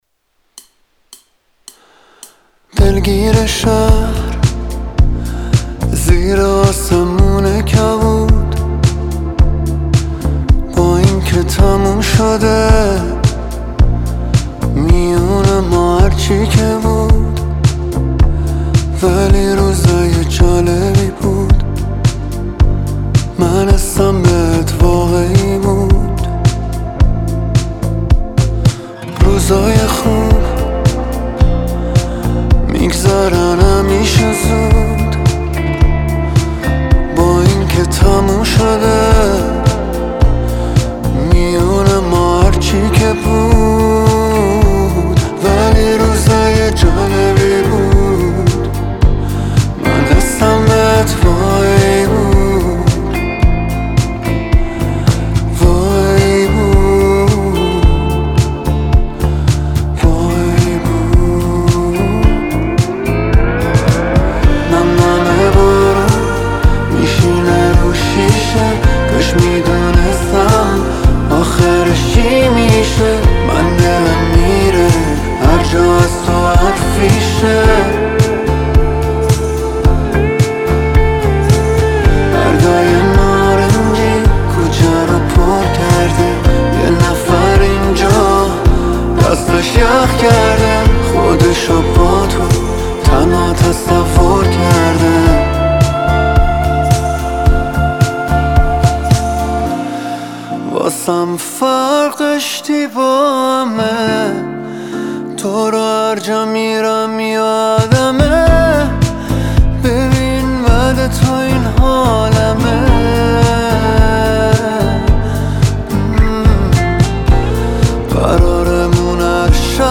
یک تک آهنگ عاشقانه
نوازنده کیبورد
نوازنده گیتار